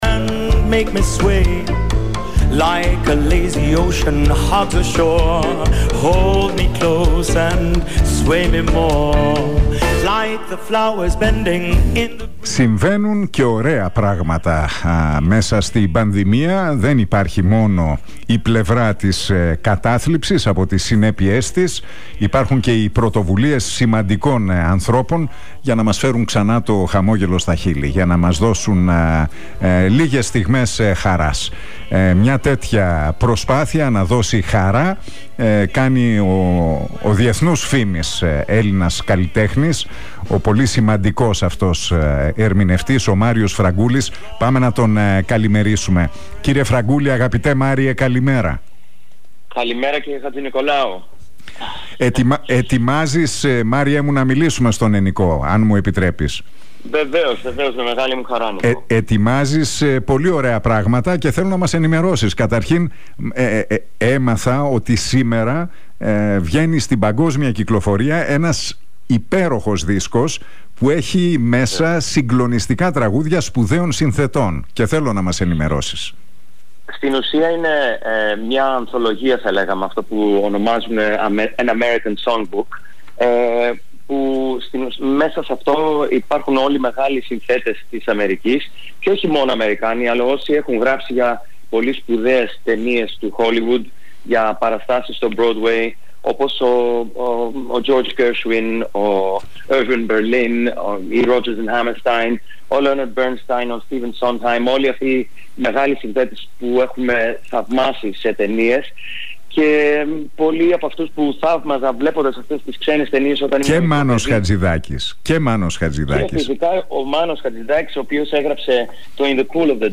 Ο Μάριος Φραγκούλης, ο διεθνούς φήμης καλλιτέχνης, με πάνω από 6 εκατομμύρια πωλήσεις στο ενεργητικό του, μίλησε στον Realfm 97,8 και στην εκπομπή του Νίκου Χατζηνικολάου για την παρουσίαση του 21ου άλμπουμ του με τίτλο «BLUE SKIES» An American Songbook, στο οποίο περιέχονται είκοσι από τα πλέον αγαπημένα τραγούδια του, όλα σπουδαίων συνθετών όπως οι Rodgers & Hammerstein, Gershwin, ColePorter, Irving Berlin, Leonard Bernstein, Michel Legrand, Stephen Sondheim και Μάνος Χατζιδάκις.